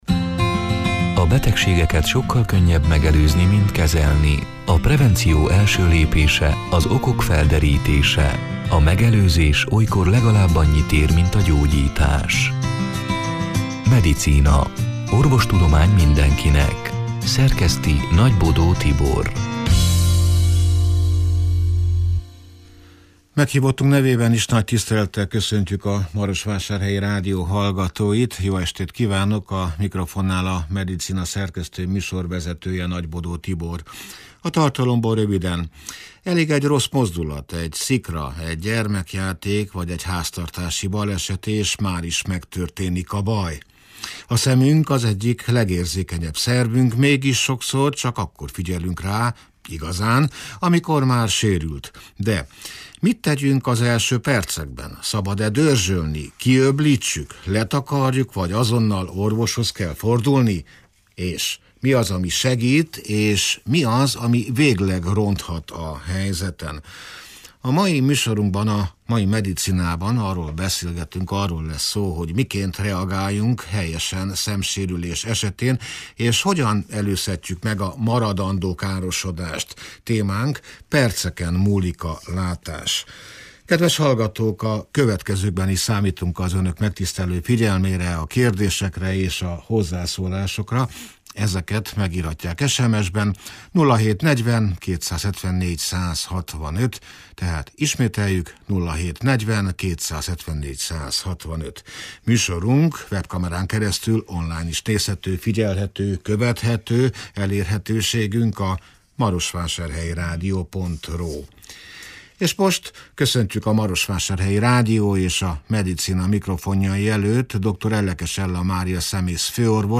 (elhangzott: 2026. február 25-én, szerdán este nyolc órától élőben)